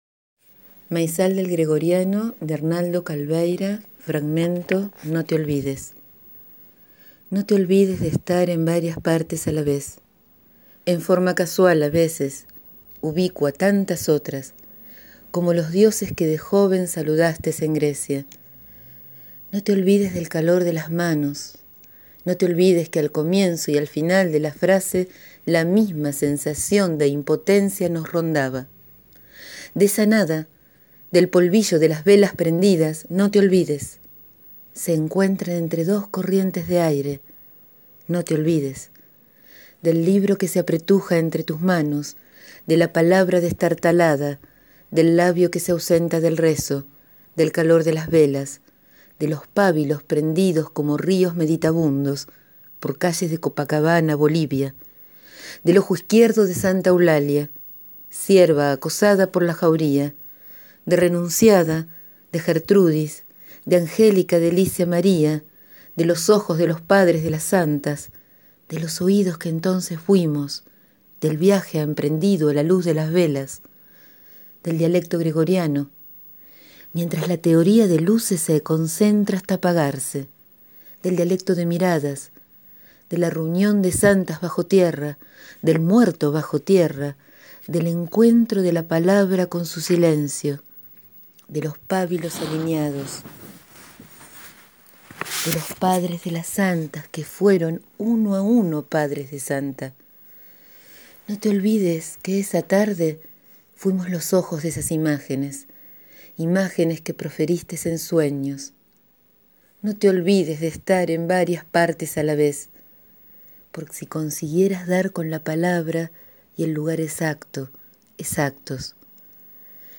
Hoy leo un fragmento del libro de poemas «Maizal del gregoriano» de Arnaldo Calveyra (1929-2015/ Argentina-Francia).